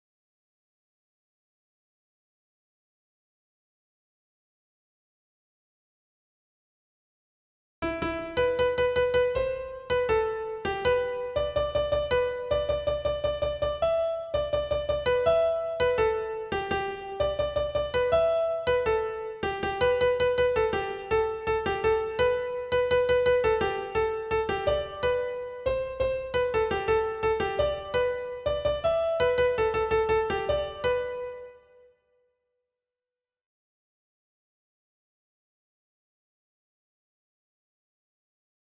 perfect piano